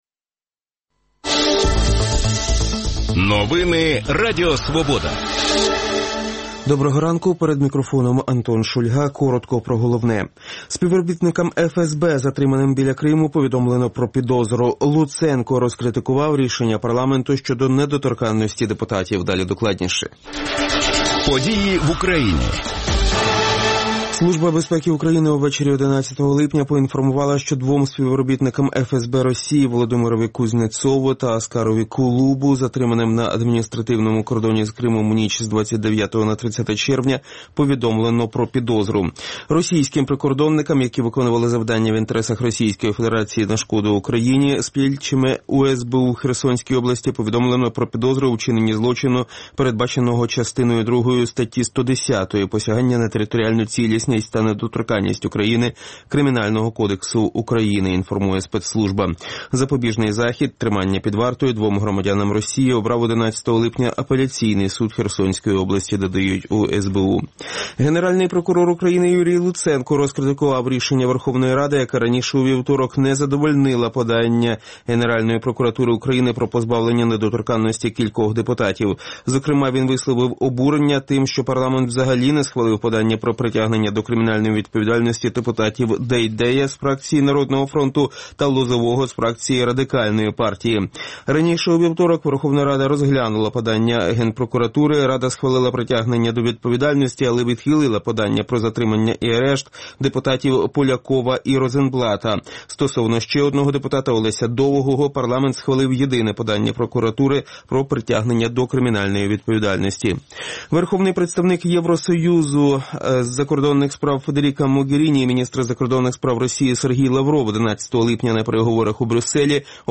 говоритиме з гостями студії